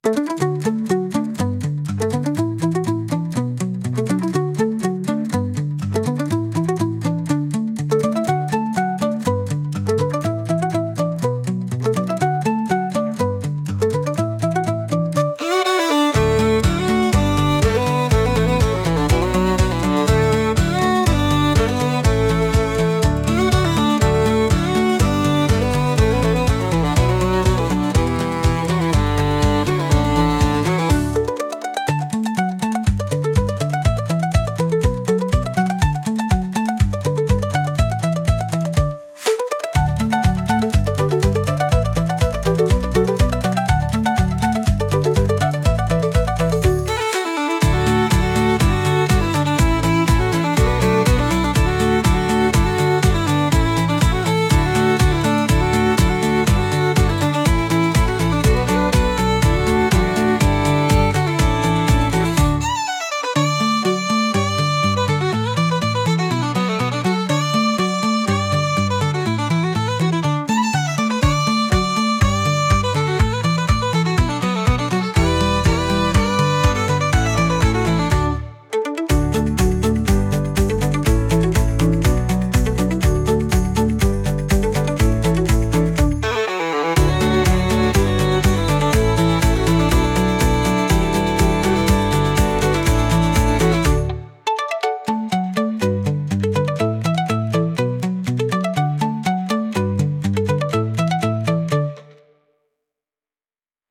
前向きになりそうな爽やかな曲です。